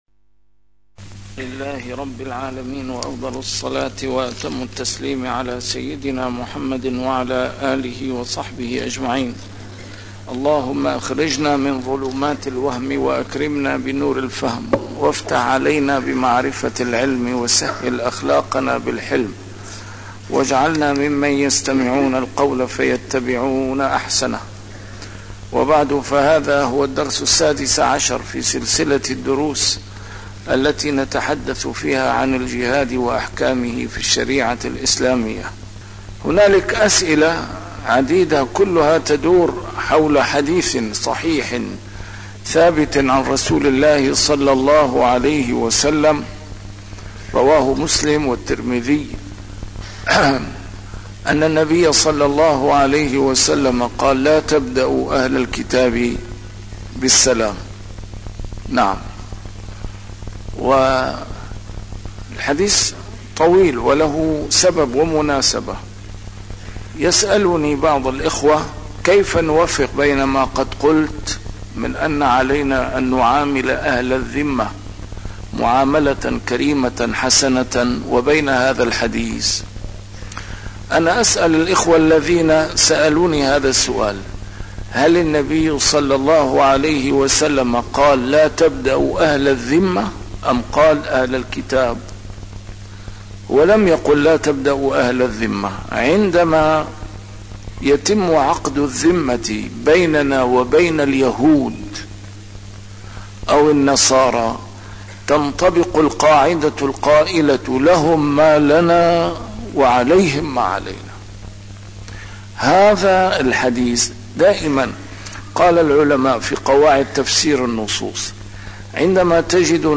A MARTYR SCHOLAR: IMAM MUHAMMAD SAEED RAMADAN AL-BOUTI - الدروس العلمية - الجهاد في الإسلام - تسجيل قديم - الدرس السادس عشر: الذمة وأحكامها - الخروج على الحاكم
الجهاد في الإسلام - تسجيل قديم - A MARTYR SCHOLAR: IMAM MUHAMMAD SAEED RAMADAN AL-BOUTI - الدروس العلمية - فقه عام - الدرس السادس عشر: الذمة وأحكامها - الخروج على الحاكم